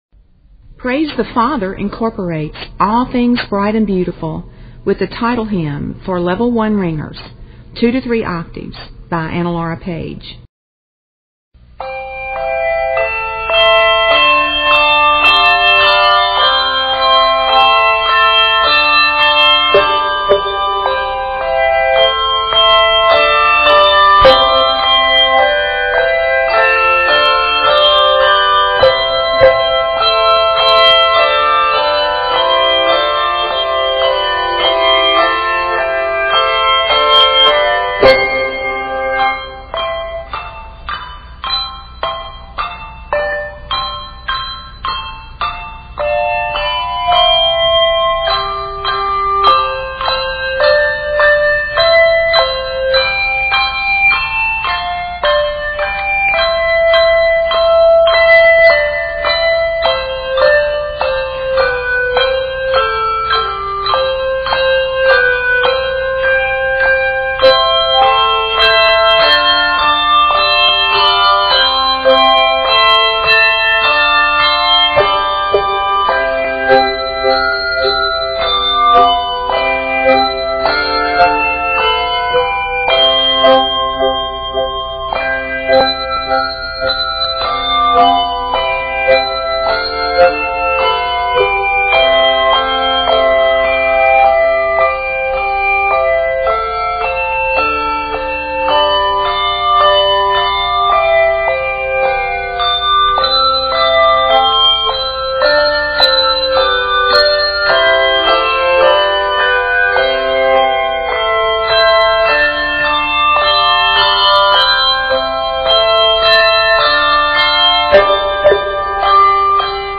is scored in C Major